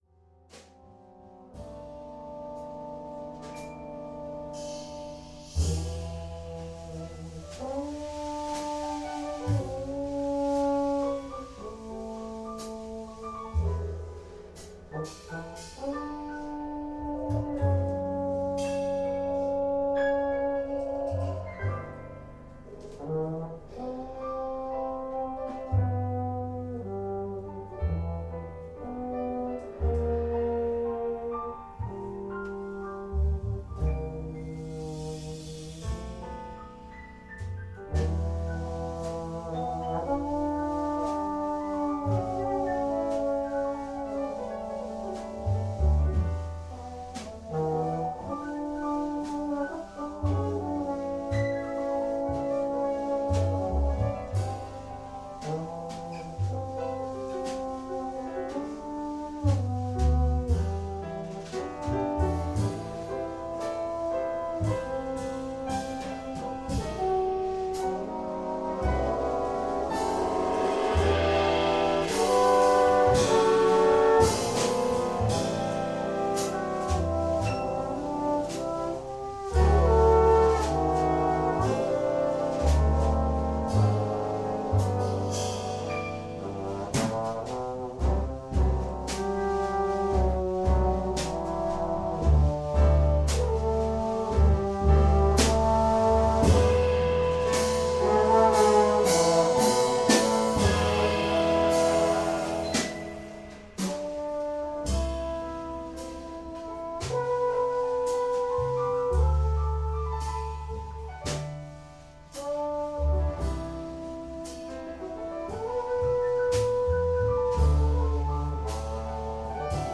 このバラードは自分の思ったことをメロディーとして書いた数少ない曲です。
くら〜い曲ですね。
この曲は大きく３つのパートに分かれています。